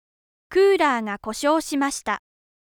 2. 母音 : 短母音と長母音